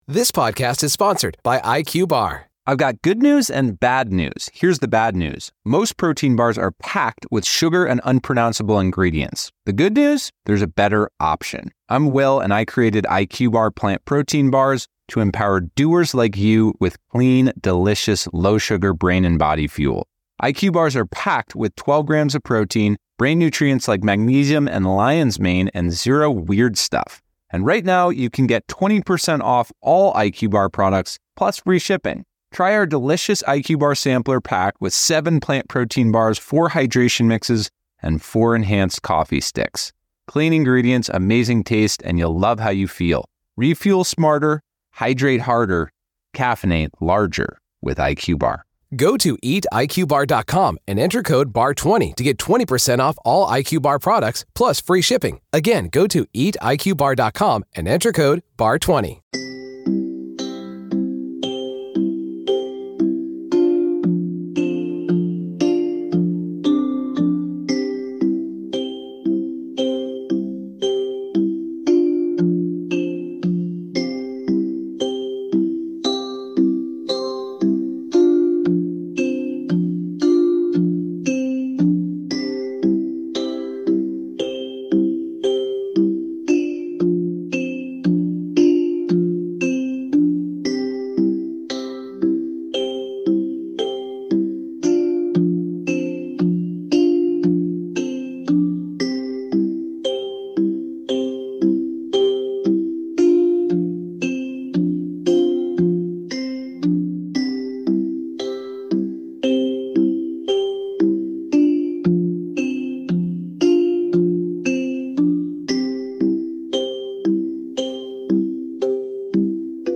Der schleichende Fuchs | The sneaking fox ~ Einschlafmusik für Kinder | Sleep Music For Kids Podcast
Eigenkomposition & eingespielt mit KI-Unterstützung.